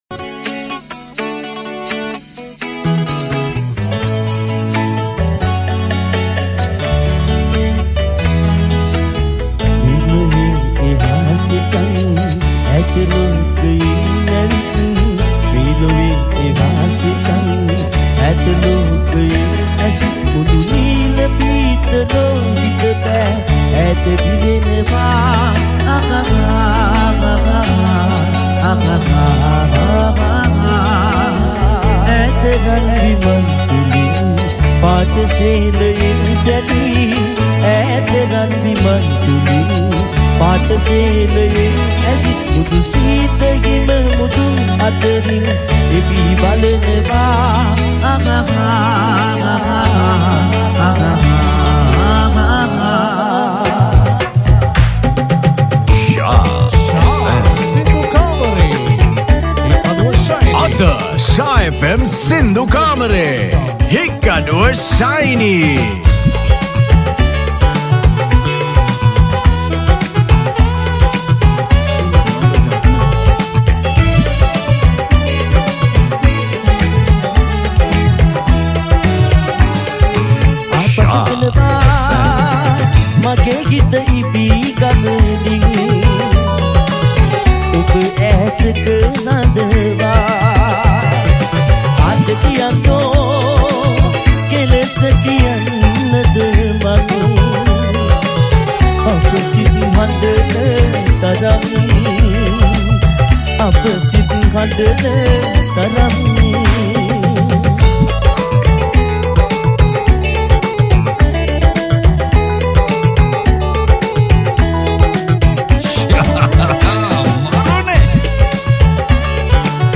Category: Live Shows